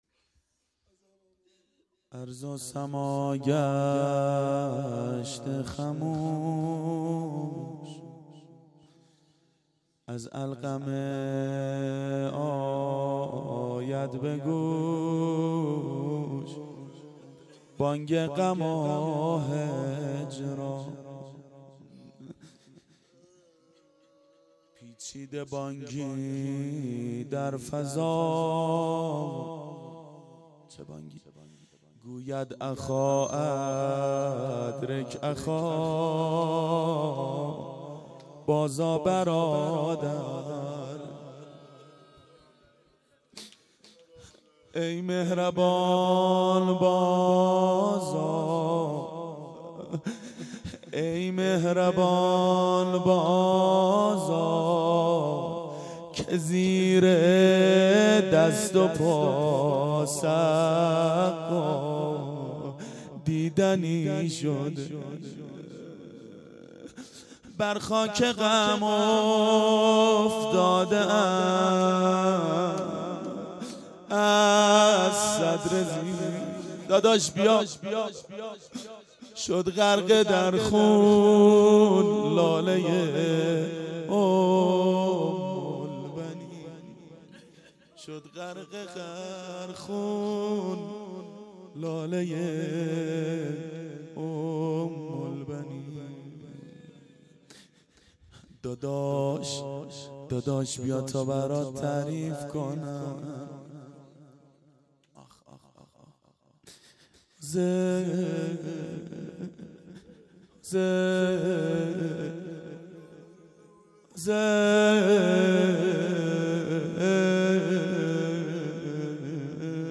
روضه شب تاسوعا
شب تاسوعا 92 هیأت عاشقان اباالفضل علیه السلام منارجنبان
01-روضه.mp3